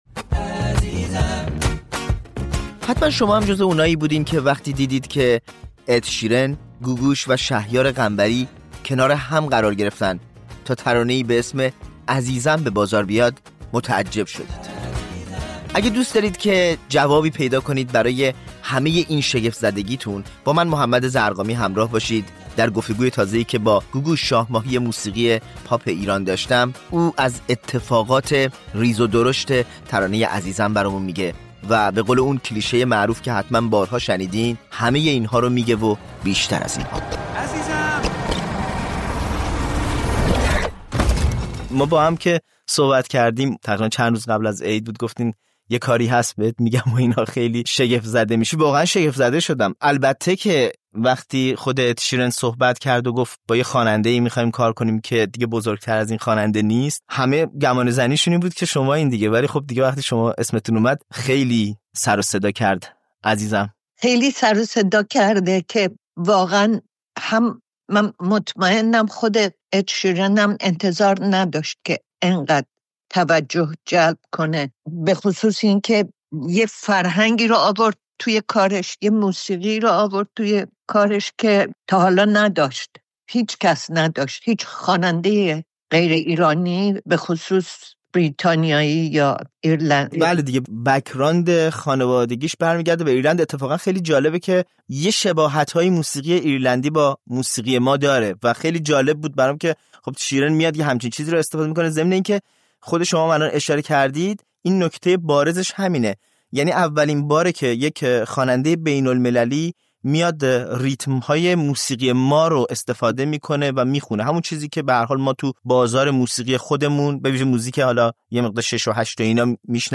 گوگوش شاه ماهی موسیقی پاپ ایران در گفت‌وگو با رادیو فردا از آغاز سال ۱۴۰۴ می‌گوید که با انتشار قطعه فراگیر «عزیزم» درکنار اِد شیرن، خوانندهٔ بریتانیایی، ورق خورد.